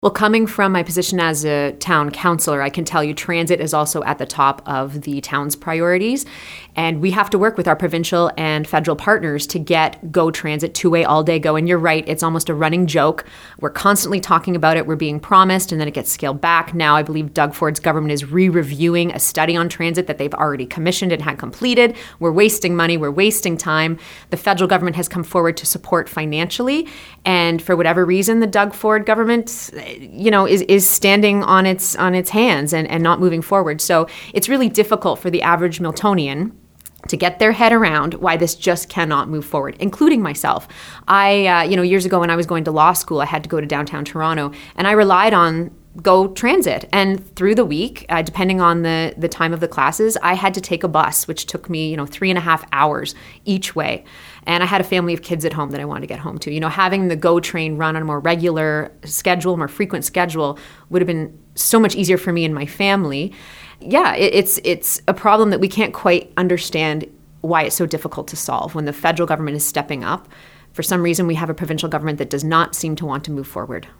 She chose to join us in-person at our Milton studios.
Here’s our interview: